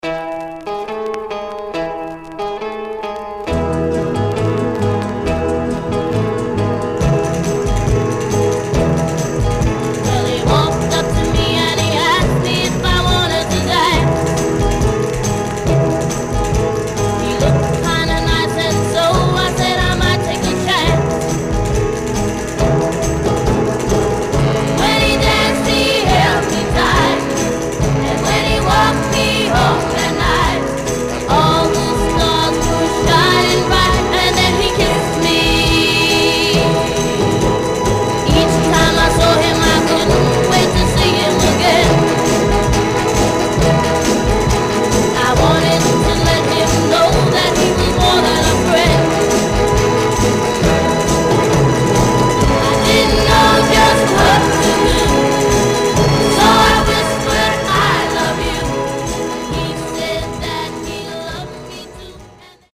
Surface noise/wear Stereo/mono Mono
White Teen Girl Groups